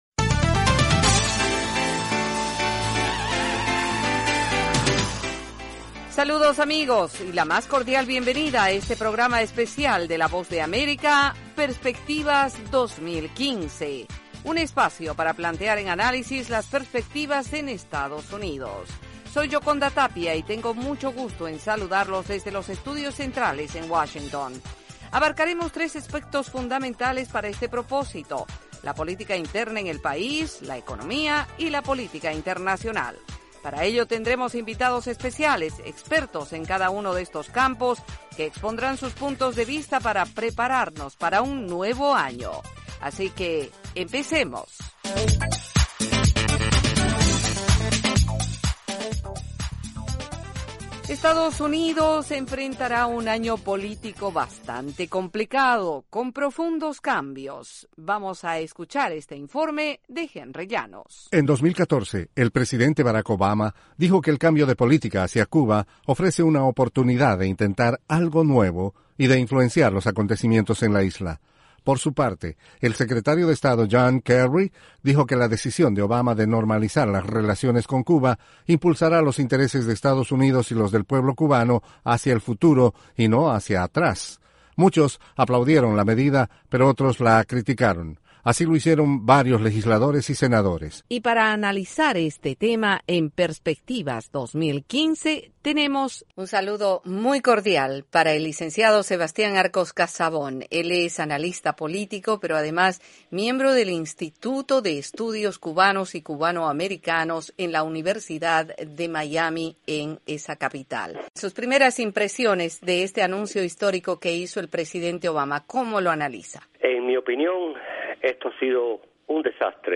Resumen de noticias en formato de audio.